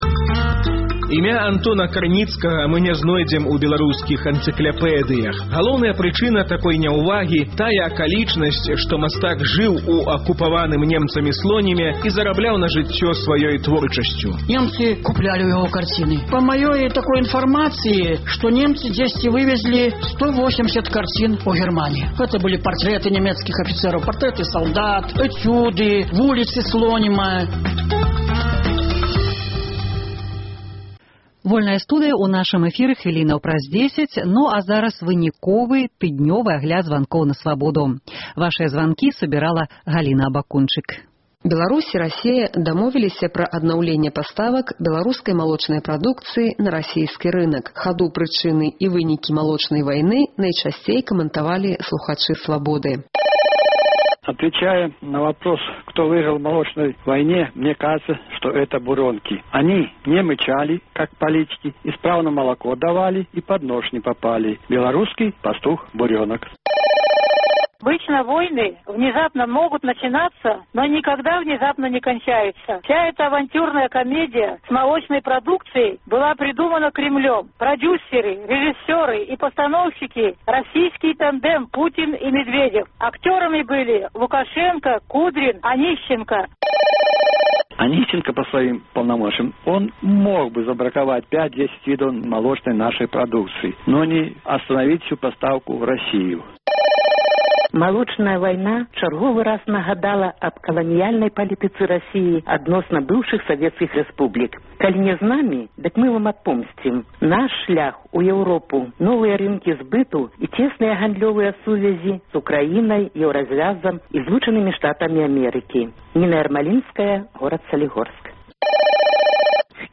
Агляд тэлефанаваньняў слухачоў